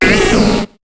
Cri de Pomdepik dans Pokémon HOME.